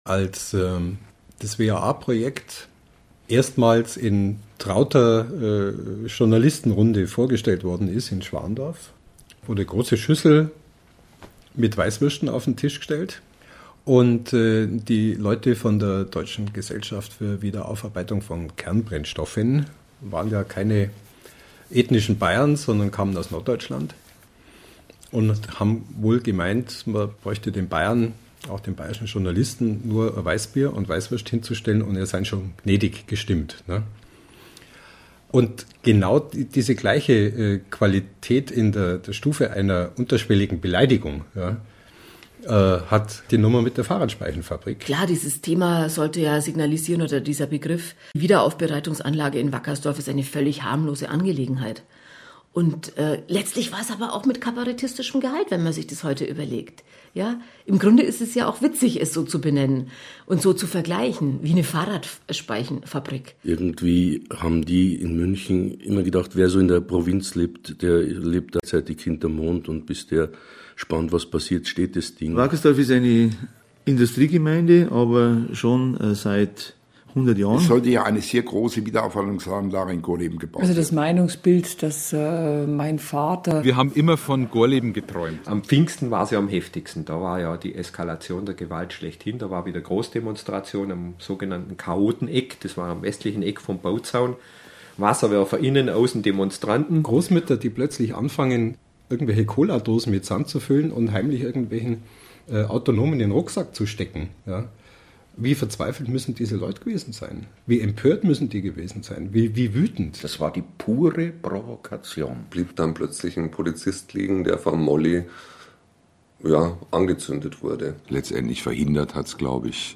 Daher findet sich im Fahrradspeichenfabrikkomplex kein allwissender Erzähler oder Kommentator, der eine vermeintlich unparteiische Wertung abgibt. Das Feature setzt sich ausschließlich aus den Berichten der verschiedensten Zeitzeugen zusammen, die die Ereignisse aus ihren je spezifischen Blickwinkeln betrachten: seinerzeitige WAA-Befürworter als auch Gegner; Politiker jeglicher Couleur von der Regional- bis zur Bundesebene, Demonstranten, Betreiber, Wissenschaftler, Polizisten, Journalisten, Anwohner, Musiker und Schriftsteller.